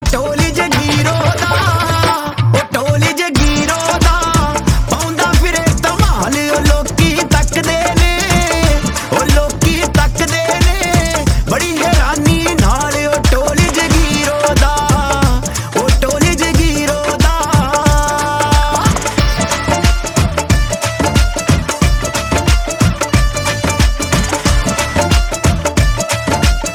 Punjabi Songs
• Simple and Lofi sound
• Crisp and clear sound